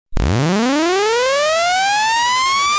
These were all recorded as WAV's and converted to MP3's to save space.
elevator.mp3